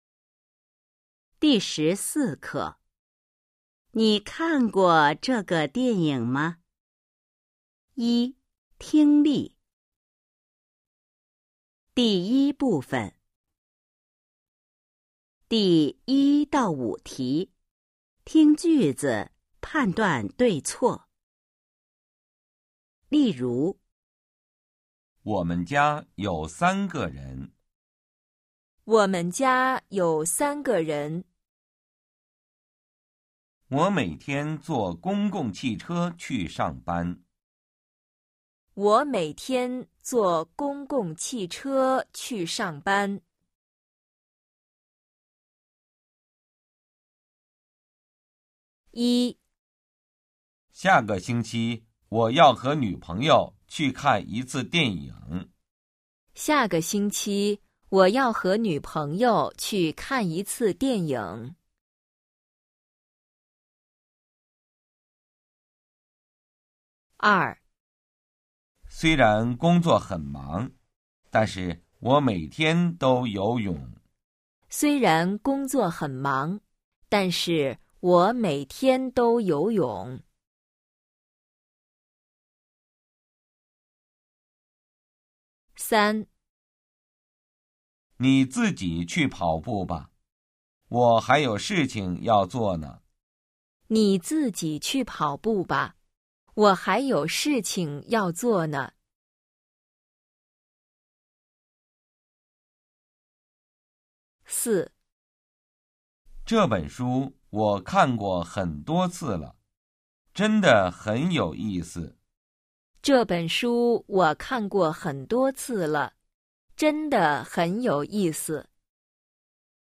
一、听力 Phần nghe 🎧 14-1